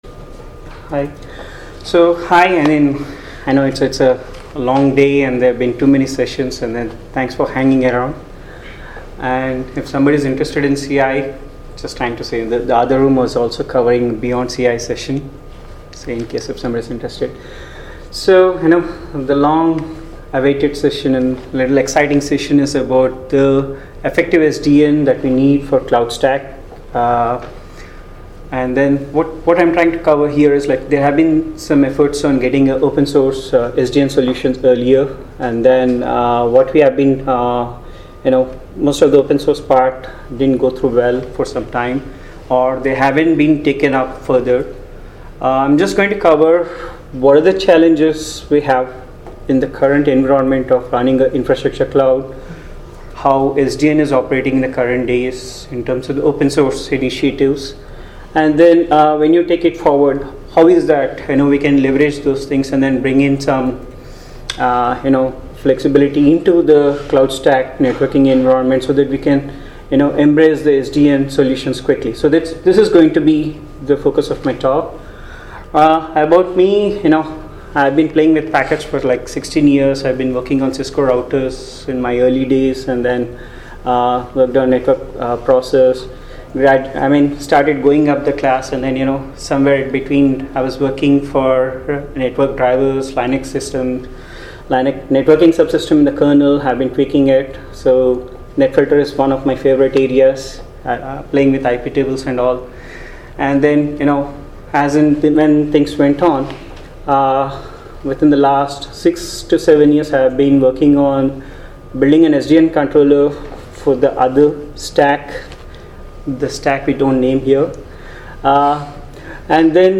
Accelerite Cloudstack Collaboration Conference
Categories: Uncategorized • Tags: ApacheCon, apacheconNA2017, Podcasts • Permalink